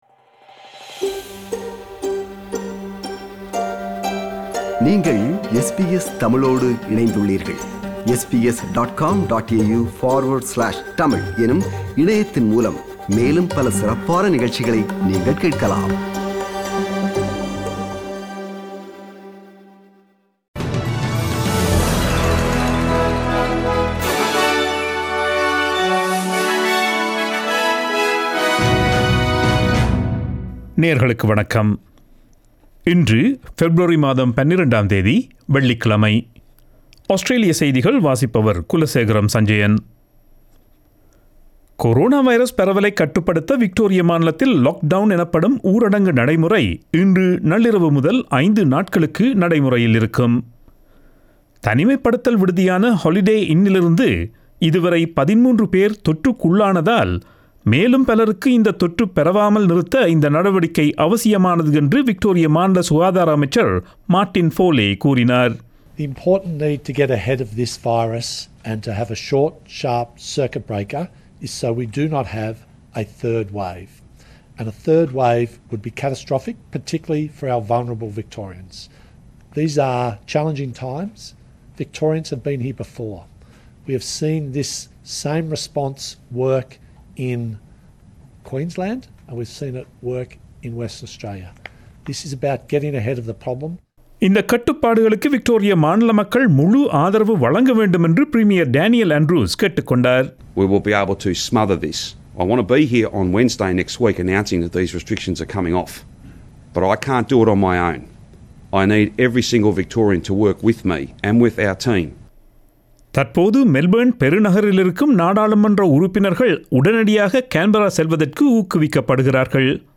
Australian news bulletin for Friday 12 February 2021.